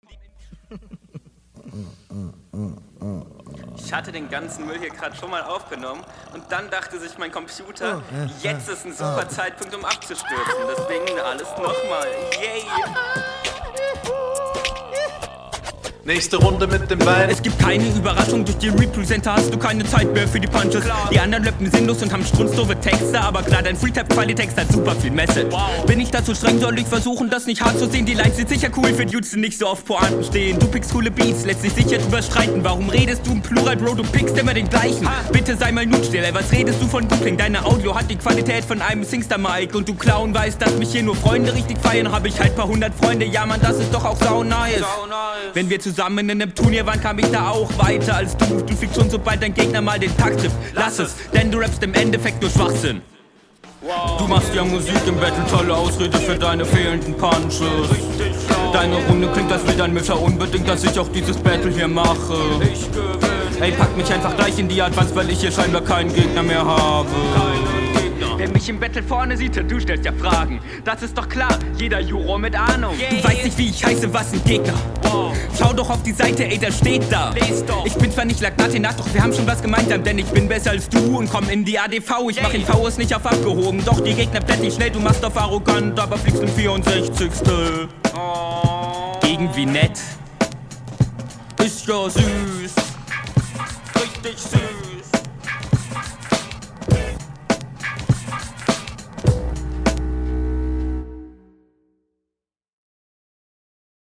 Klingst wieder recht unroutiniert, bist zwar meist im takt aber man hört die unsicherheit halt …